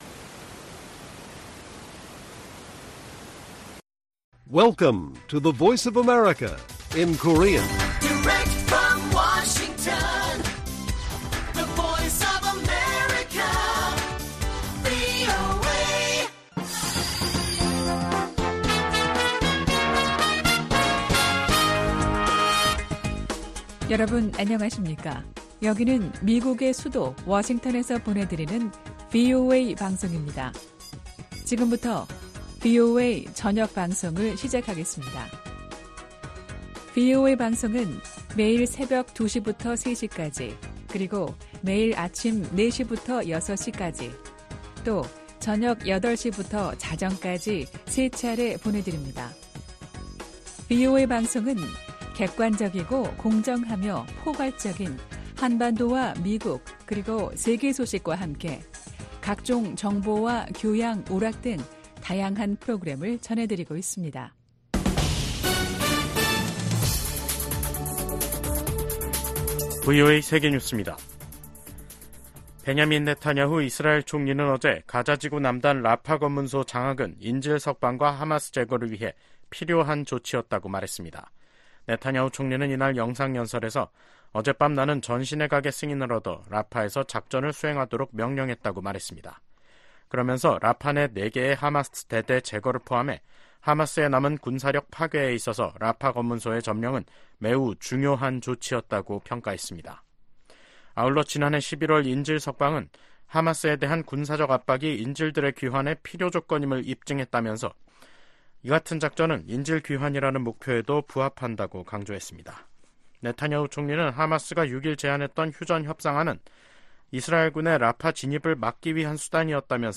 VOA 한국어 간판 뉴스 프로그램 '뉴스 투데이', 2024년 5월 8일 1부 방송입니다. 도널드 트럼프 전 대통령은 자신이 대통령에 당선되면 한국이 주한미군 주둔 비용을 더 많이 부담하지 않을 경우 주한미군을 철수할 수 있음을 시사했습니다. 러시아 회사가 수천 톤에 달하는 유류를 북한으로 운송할 유조선을 찾는다는 공고문을 냈습니다.